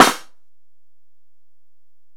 Snare (20).wav